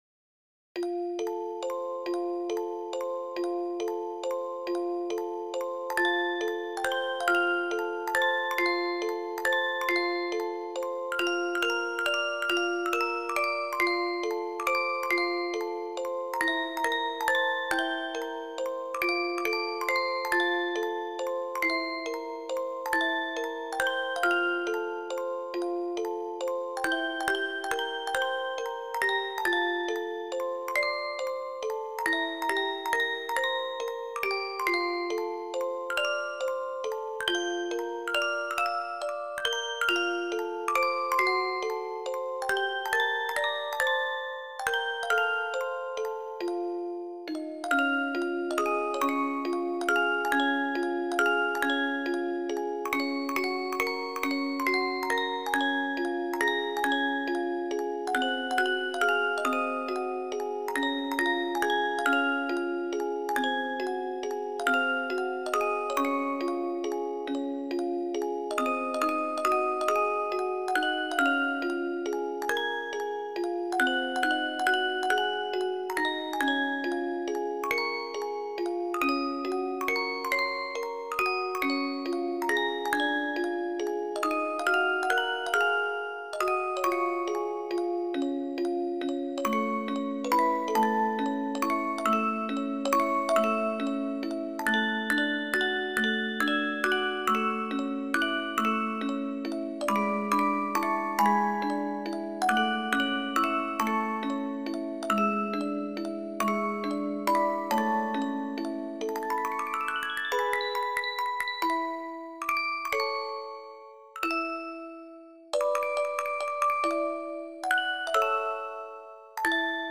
季節の歌－MP3オルゴール音楽素材